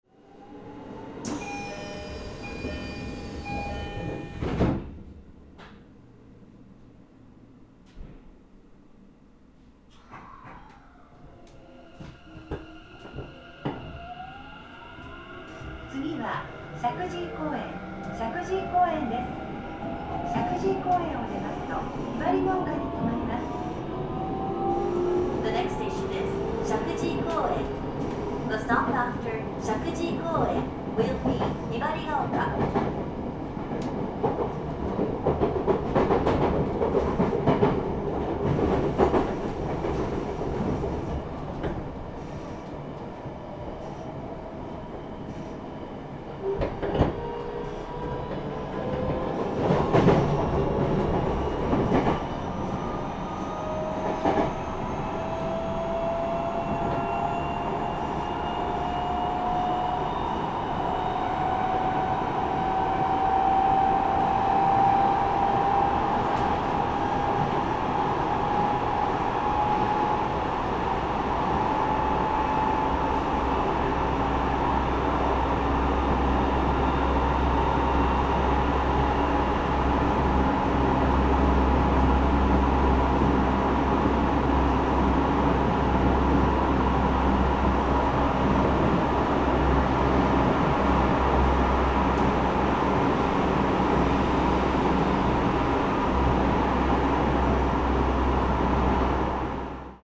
鉄道走行音ＣＤ 真栄館［既刊情報・東京地下鉄10000系 副都心線急行・西武池袋線快速］
録音車両：１０２１４
今回は、西武池袋線内で快速となる列車を、東京地下鉄最初の新形式・10000系で録音しました。加減速を繰り返す地下線での急行運転をお楽しみください。
Tokyo-metro10000.mp3